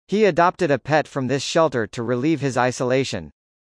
【ノーマル・スピード】
❖ He adopted a:母音＋母音、子音+母音が連結する個所です。
relieve:最初の音節「re」を弱くあいまいに前倒しで発音します。